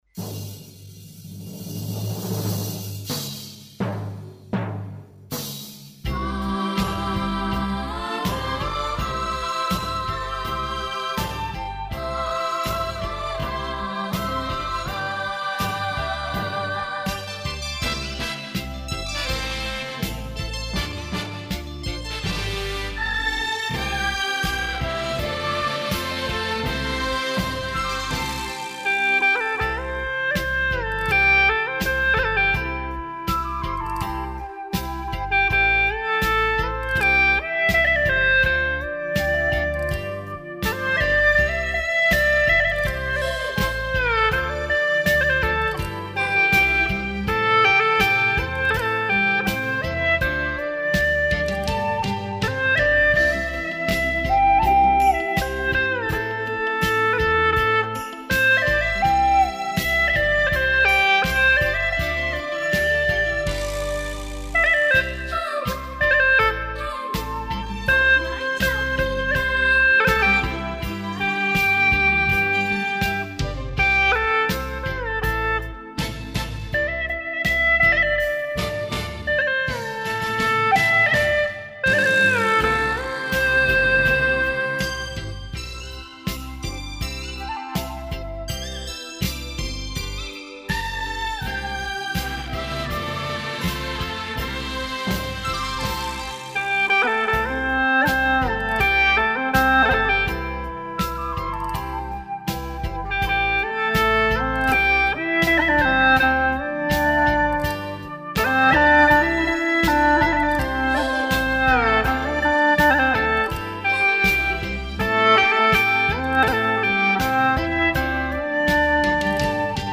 调式 : C 曲类 : 流行